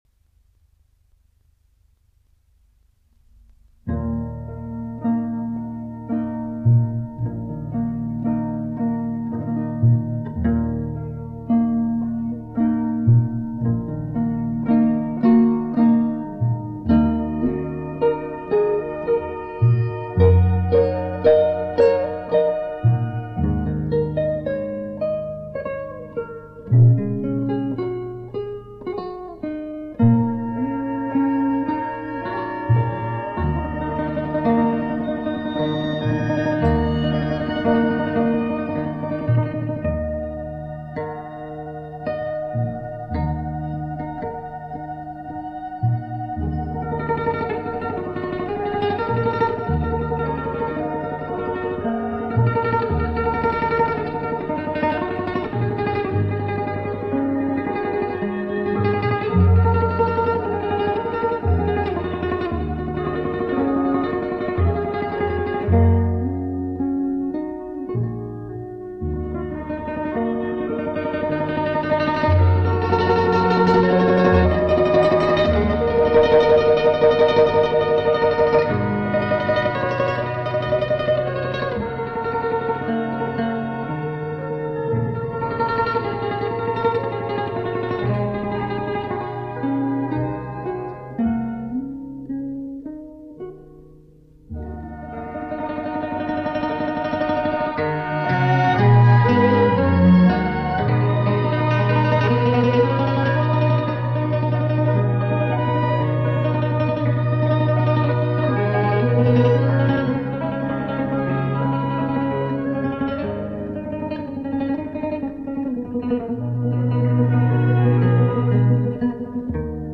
卡式磁带电脑录制·未作降噪处理
尽管转录设备很一般，音效与CD不可同日而语，但是仍能听出其中的精髓。